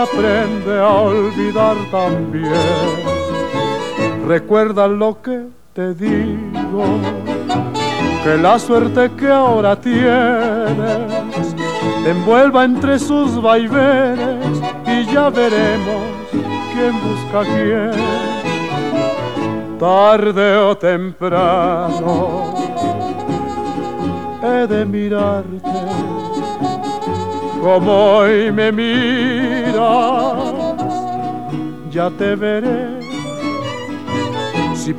Baladas y Boleros Latin Música Mexicana